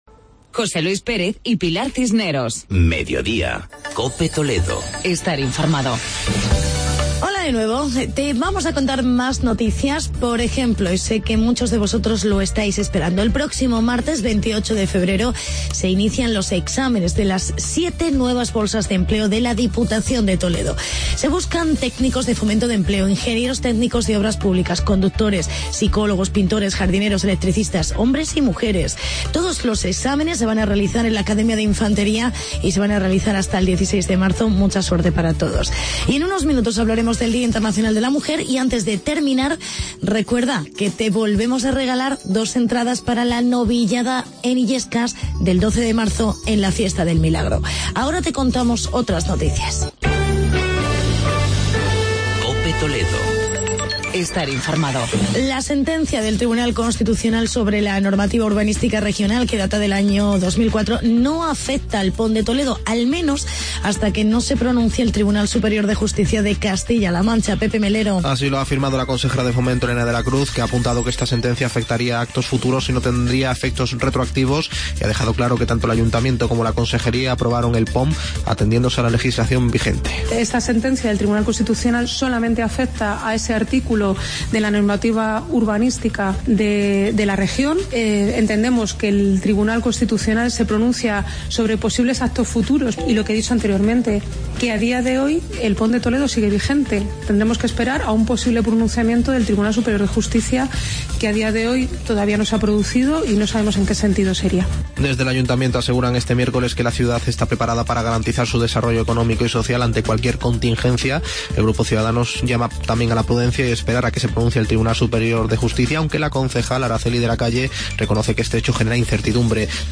Actualidad y entrevista con la concejal de igualdad Ana SantaMaría.